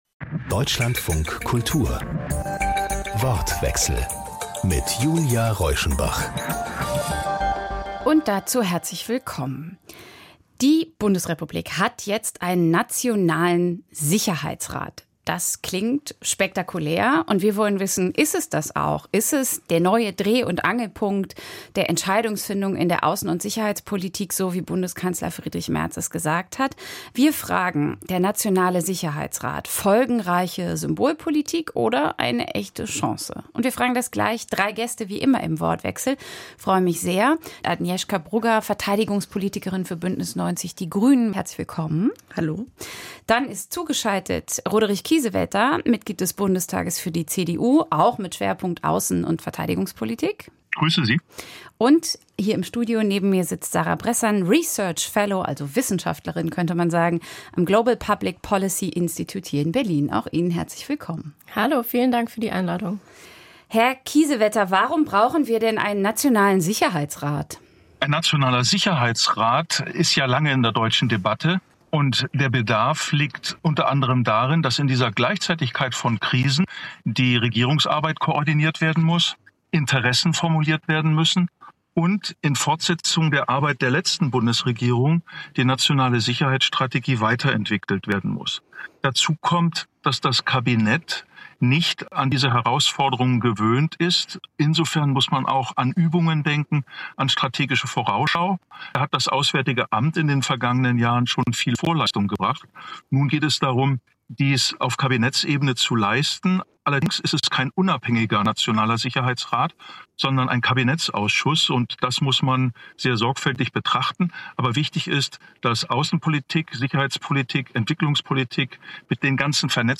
Der Wortwechsel ist das Diskussionsforum bei Deutschlandfunk Kultur – mit interessanten... Mehr anzeigen